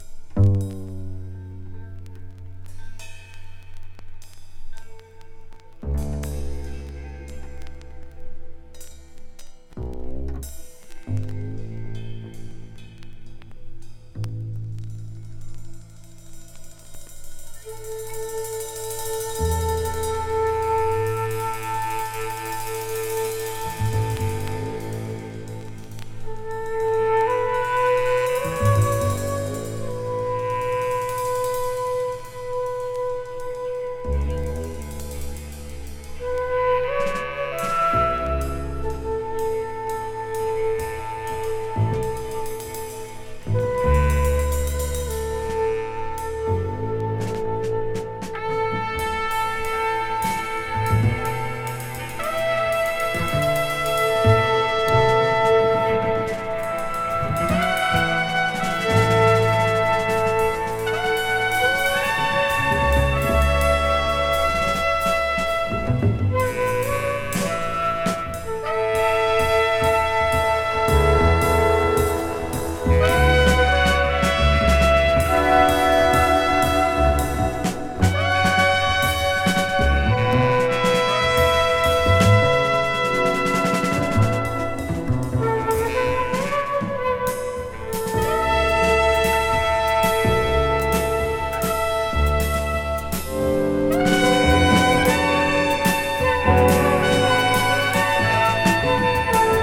ヒンヤリとしつつも、どことなく人肌を感じるサウンドが素晴らしい遠景ジャズ